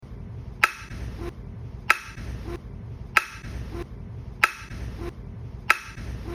can_opener.mp3